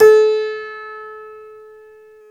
Index of /90_sSampleCDs/Club-50 - Foundations Roland/PNO_xTack Piano/PNO_xTack Pno 1M